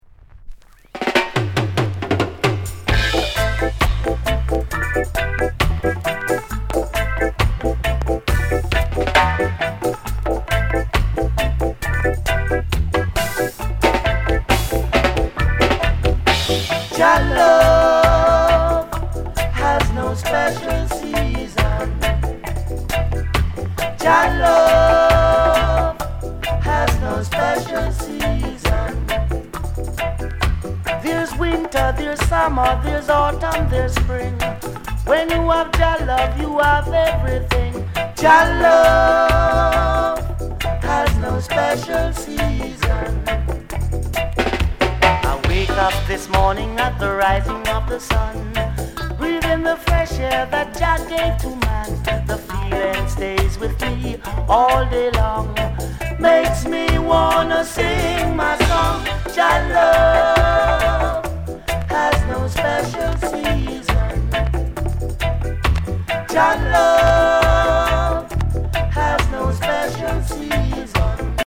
VERY RARE ROOTS